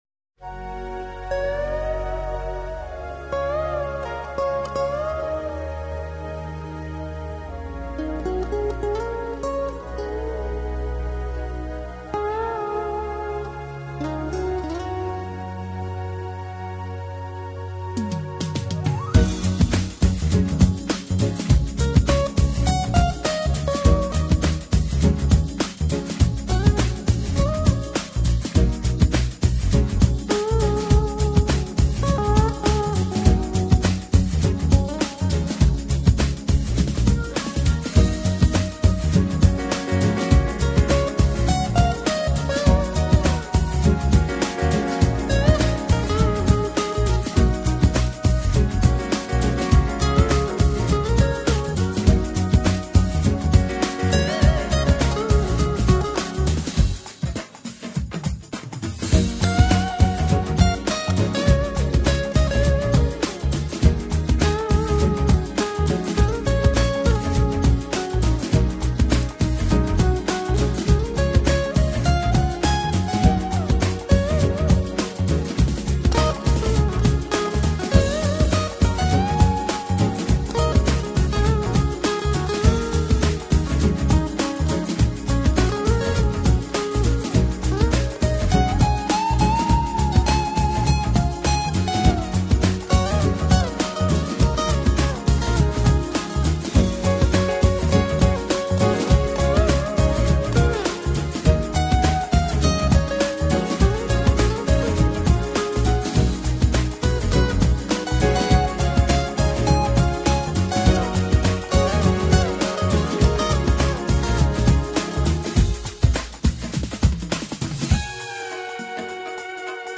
唱片的风格动感而 且很有朝气